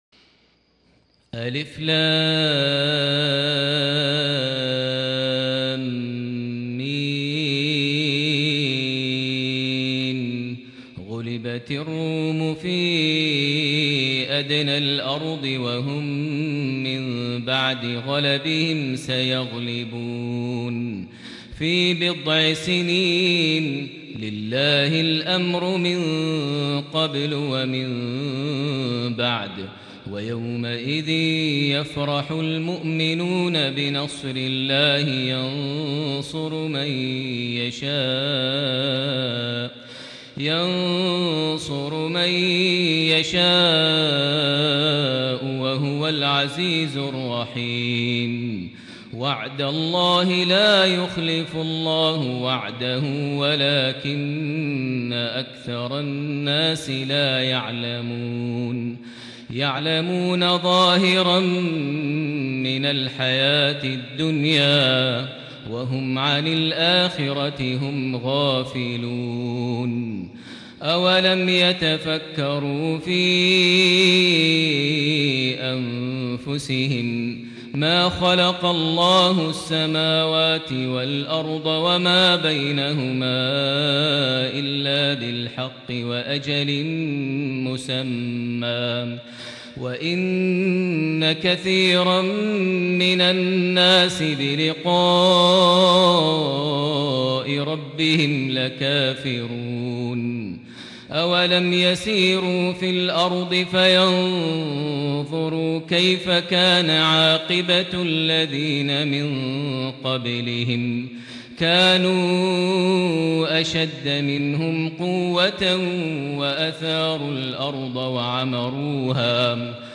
صلاة الفجر ١١ رجب ١٤٤٠هـ سورة الروم ١-٢٥ > 1440 هـ > الفروض - تلاوات ماهر المعيقلي